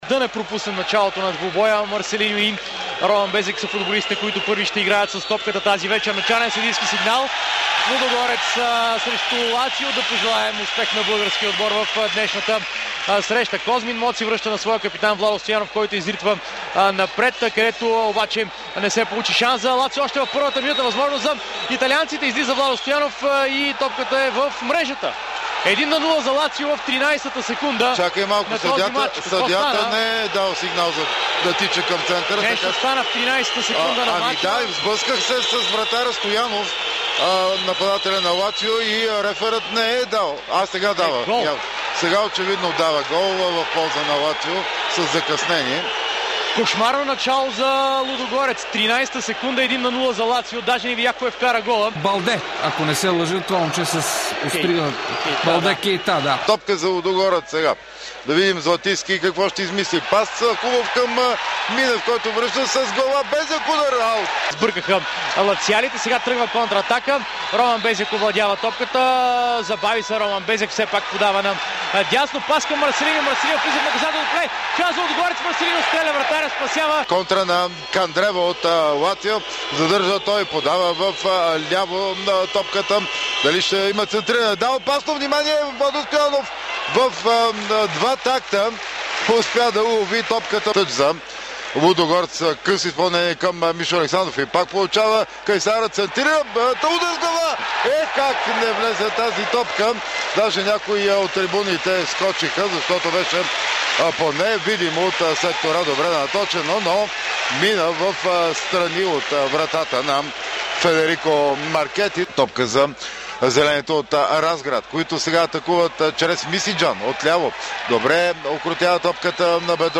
Датата е 27 февруари, годината – 2014, мястото – стадион Васил Левски.
Футболните коментатори на Дарик радио